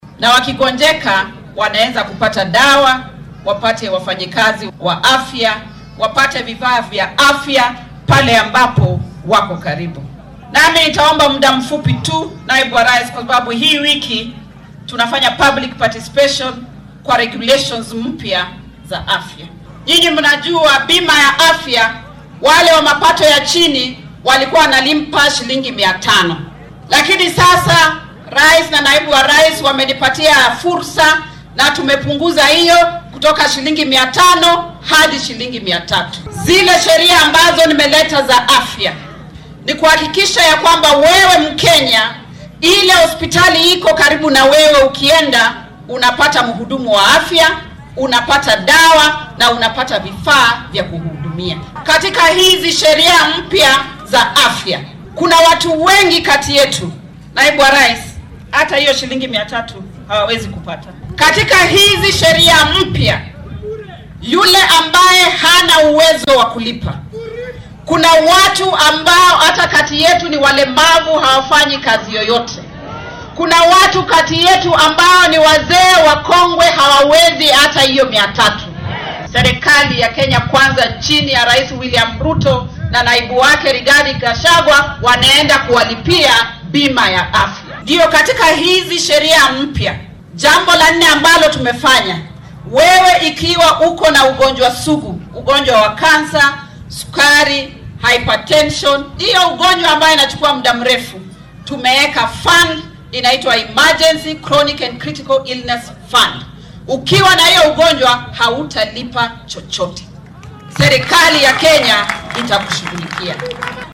Xilli ay ku sugnayd deegaanka Kwanza ee ismaamulka Trans Nzoia ayay carrabka ku adkeysay in caymiska cusub ee caafimaadka uu xambaarsan yahay waxyaabo badan oo faa’iido u leh bulshada. Waxay tusaale u soo qaadatay in lacagaha bil kasta lagu bixiyo NHIF ee ah 500 oo shilin in laga dhigay 300 oo shilin.